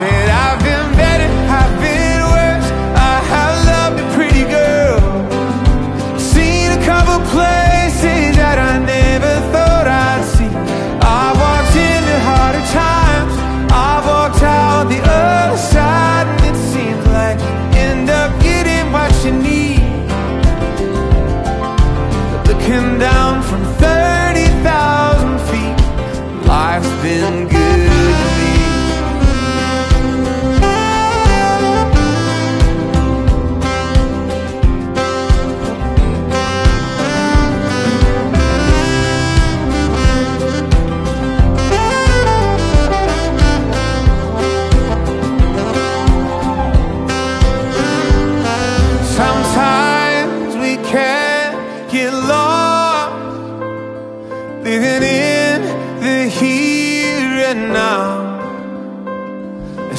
Both amplifiers have integrated DACs and plenty of power, but they offer very different sounds. The Hegel H190 has a warmer, more laid-back sound, while the NAD C399 is a bit more aggressive and less warm.